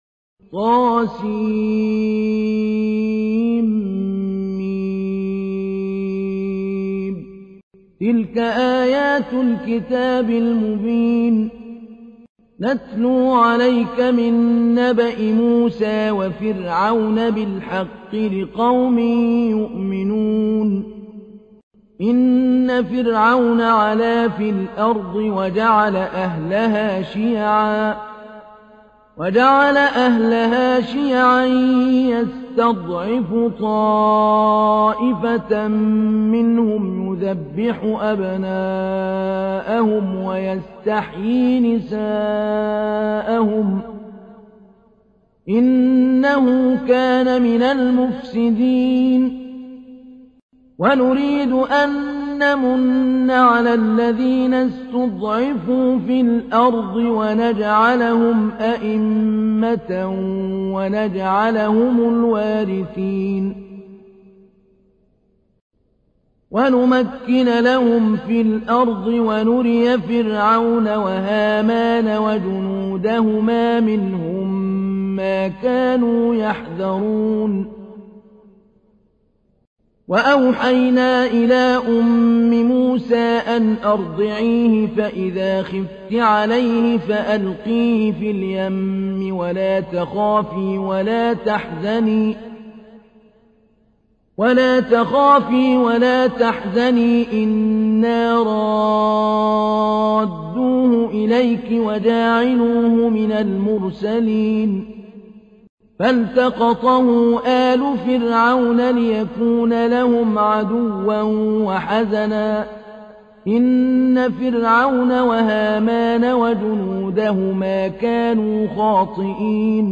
تحميل : 28. سورة القصص / القارئ محمود علي البنا / القرآن الكريم / موقع يا حسين